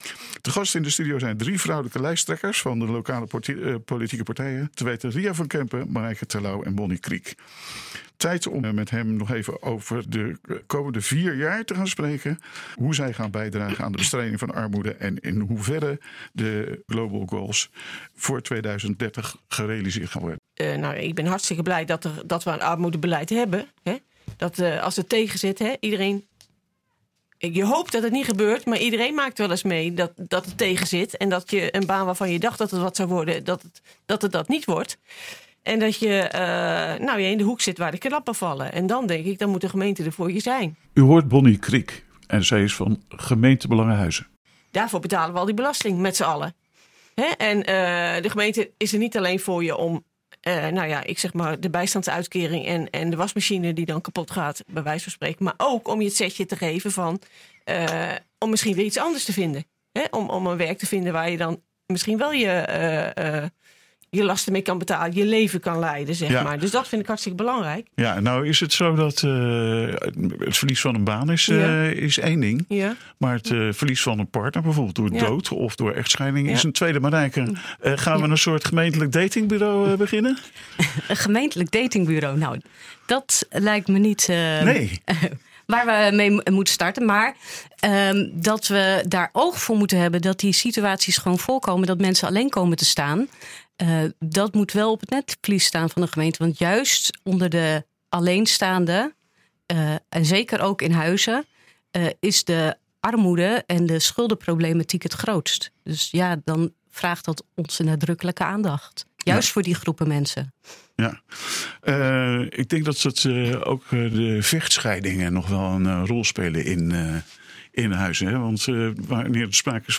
Vrijdagmiddag in een verlaten studio druppelden de fractievoorzitters van Gemeentebelangen, voorheen Dorpsgbelangen, PITHuizen en ChristenUnie Huizen binnen. Drie vrouwen, Bonnie Kriek, Ria van Kempen en Marijke Terlouw, goed voorbereid klaar voor koffie, microfoon en een goed gesprek.